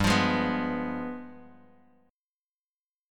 G7b9 Chord
Listen to G7b9 strummed